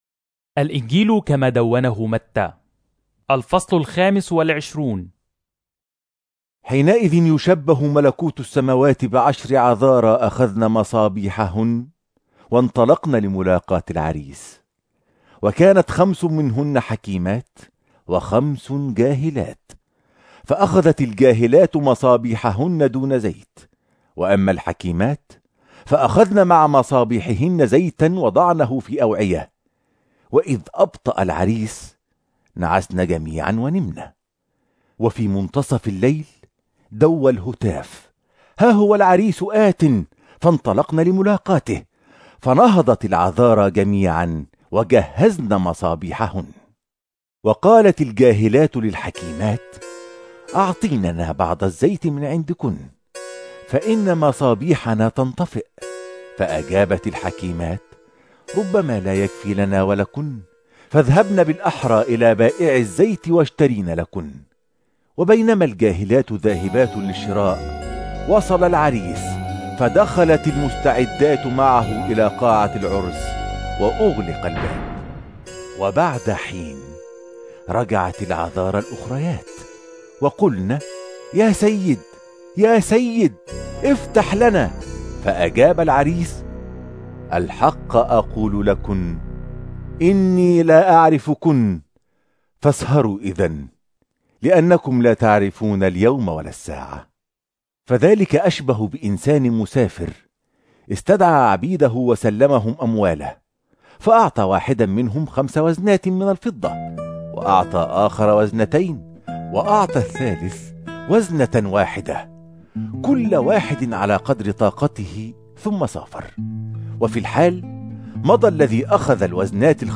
Arabic Audio Drama Bible New Testament - United Evangelical Christian Fellowship(UECF), New Jersey - Popular Christian Website Telugu Hindi Tamil Malayalam Indian Christian audio Songs and daily bible devotions